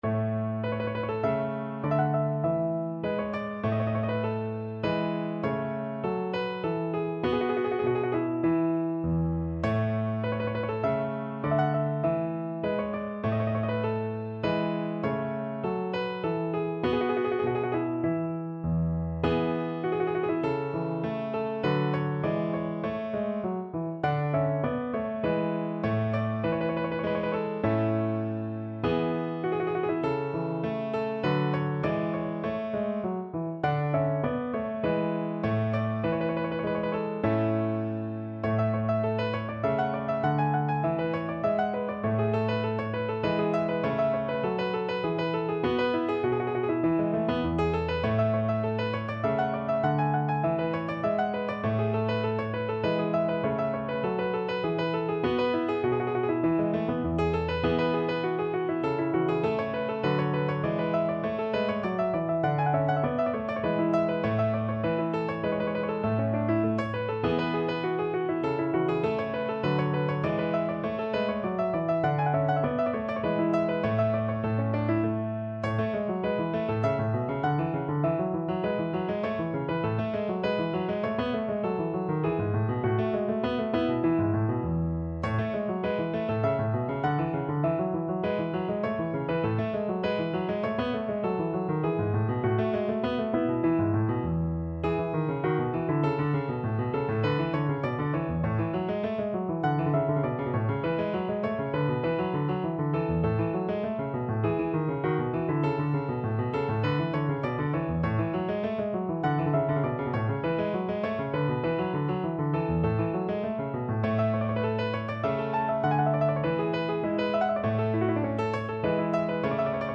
No parts available for this pieces as it is for solo piano.
Allegretto
4/4 (View more 4/4 Music)
Piano  (View more Intermediate Piano Music)
Classical (View more Classical Piano Music)